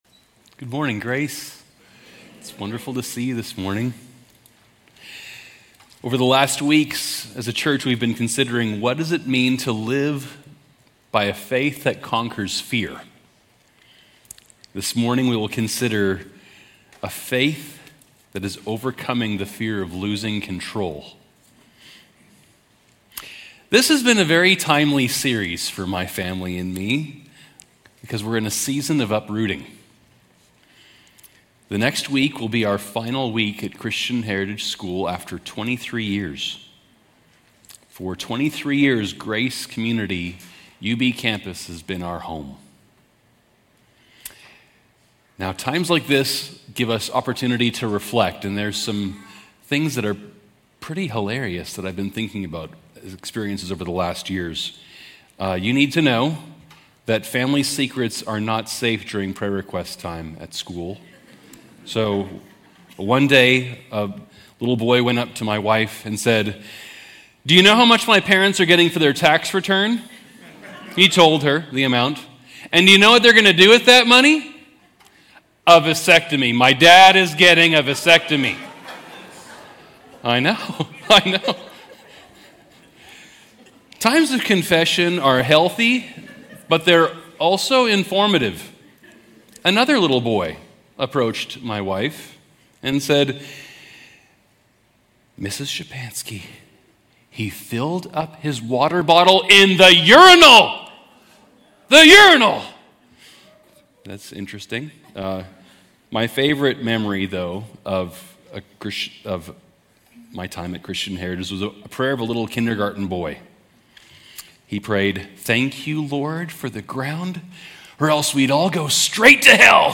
Grace Community Church University Blvd Campus Sermons 5_11 University Blvd Campus May 11 2025 | 00:22:57 1x 00:00 / 00:22:57 Subscribe Share RSS Feed Share Link Embed